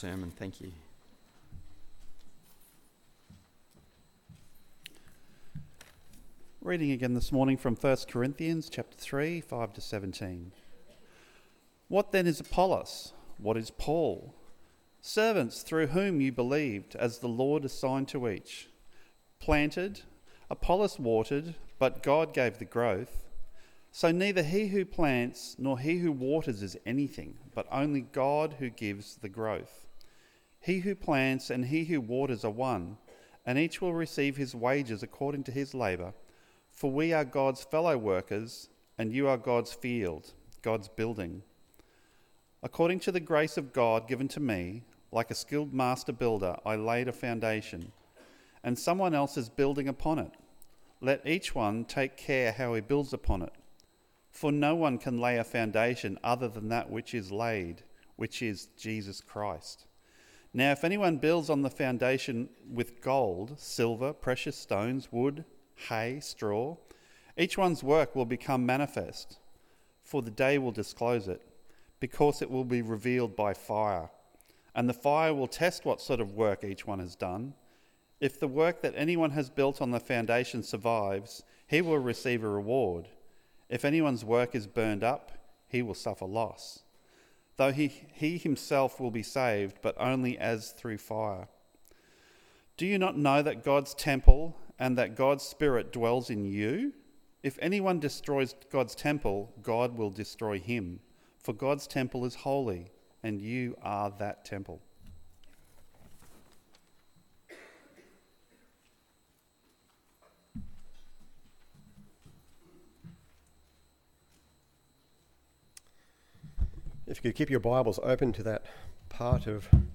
What God Wants Us To Do In His Church AM Service